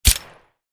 wpn_pistol_10mm_silenced_2d.wav